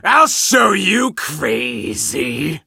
monster_stu_start_vo_02.ogg